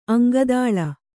♪ aŋgadāḷa